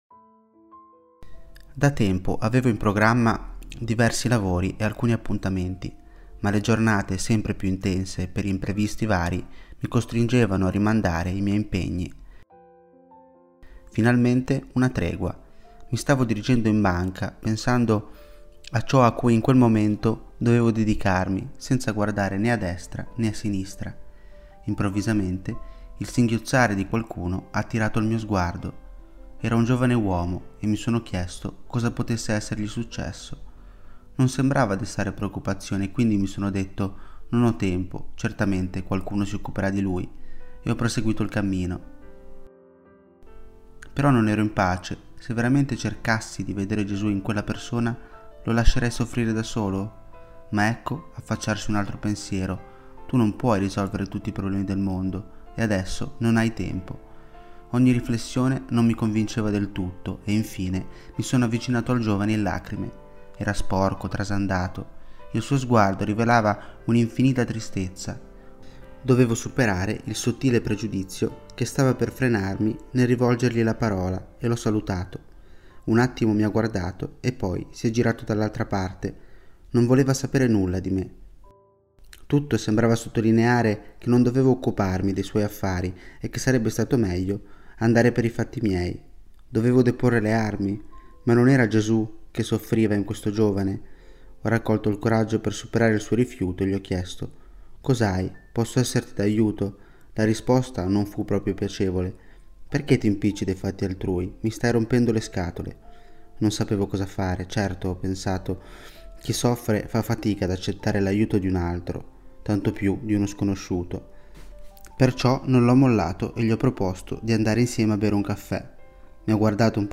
Persona e famiglia > Audioletture